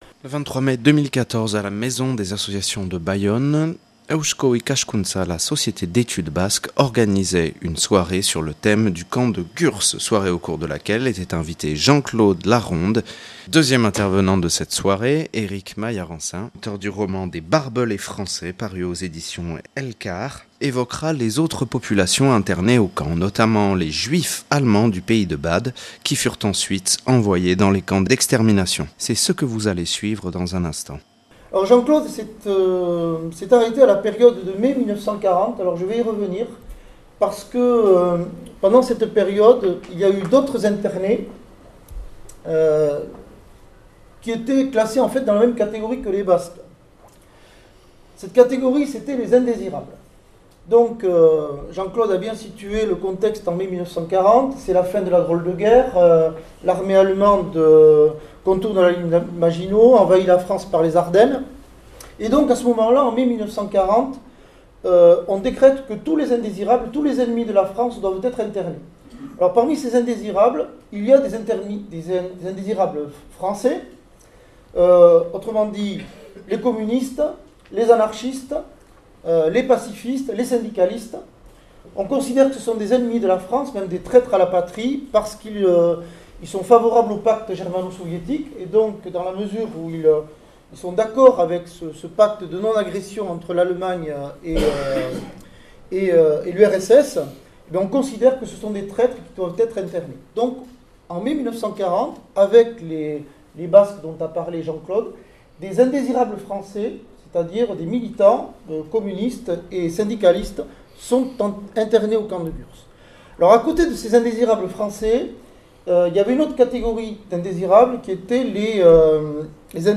(Enregistré le 23/05/2015 à la Maison des associations de Bayonne).